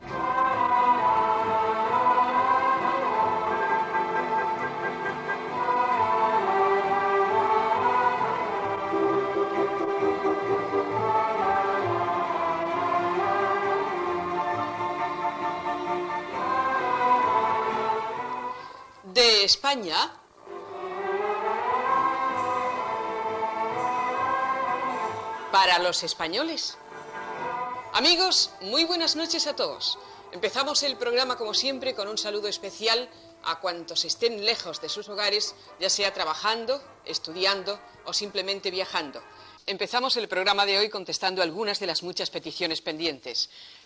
Sintonia del programa i presentació.